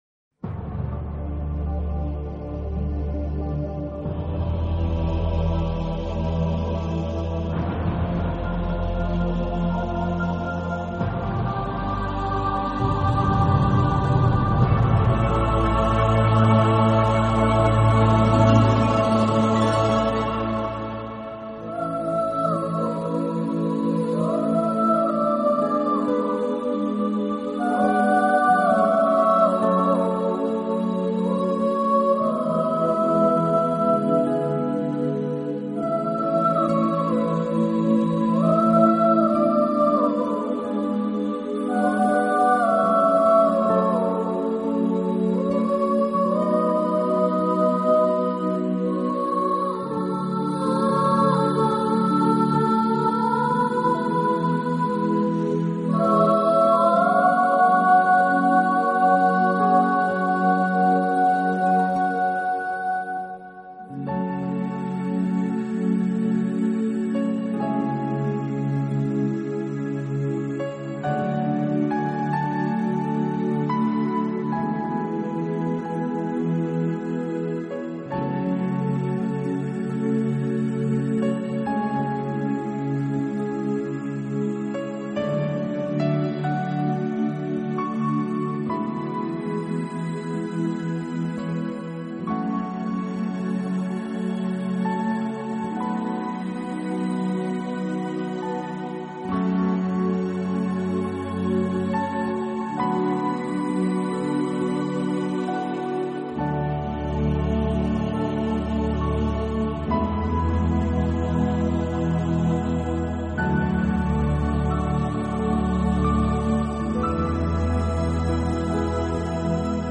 以舒缓、轻柔的旋律为主基调，适当加以自然 海潮声，伴着缥缈空灵的女声哼唱